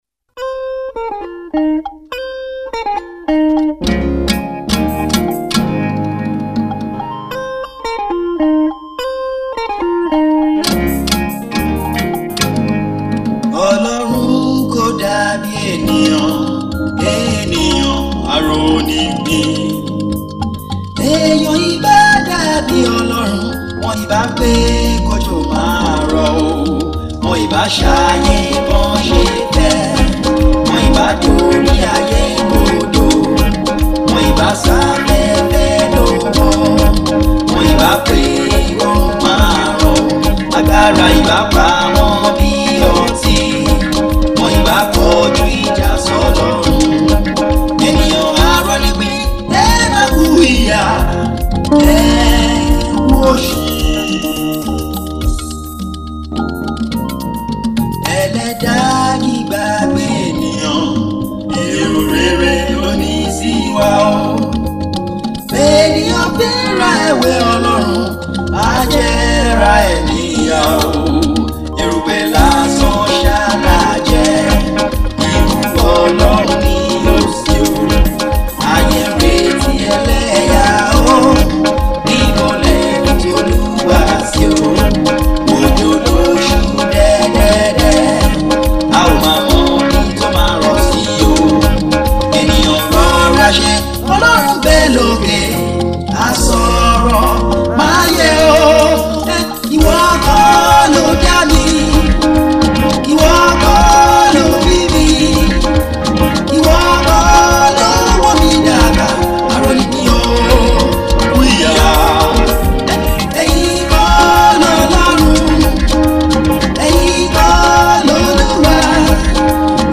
First Version Below